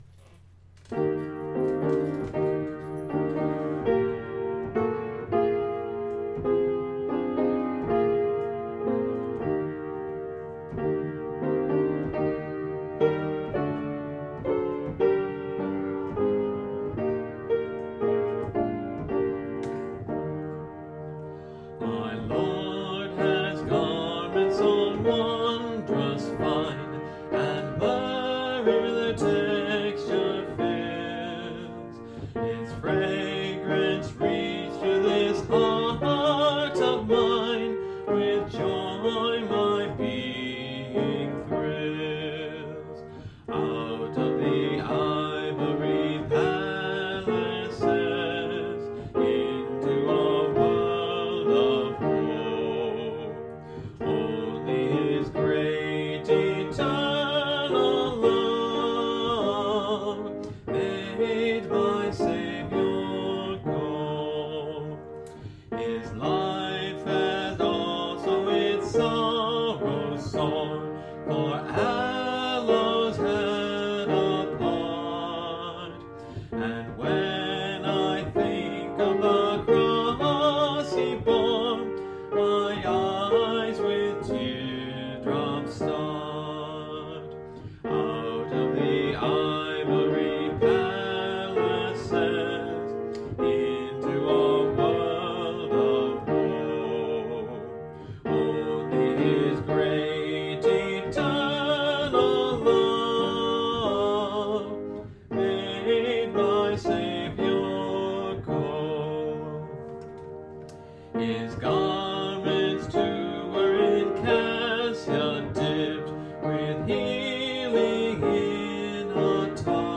(Part of a series singing through the hymnbook I grew up with: Great Hymns of the Faith)
The one thing interesting about this hymn, melodically, is that in the Refrain the melody passes back and forth between the alto and soprano lines.  There is actually a descant written in.